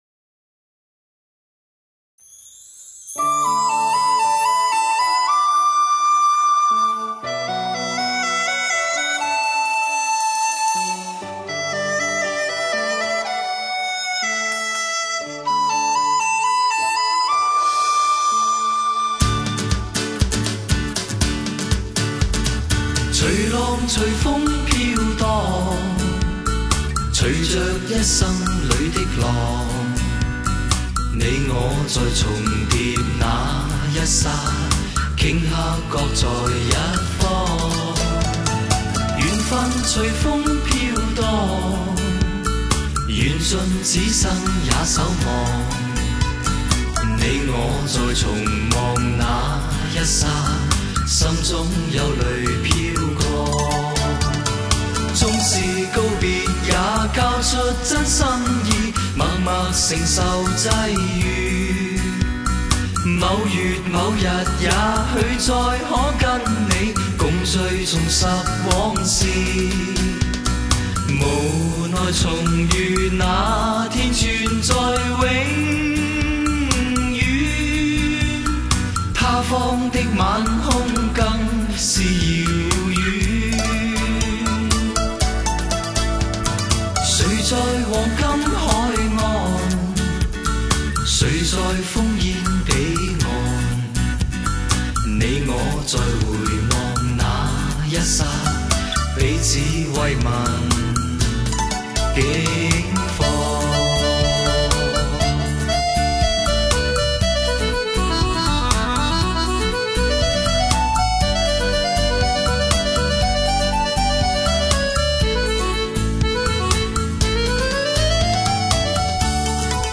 粤语灵魂歌
低吟浅唱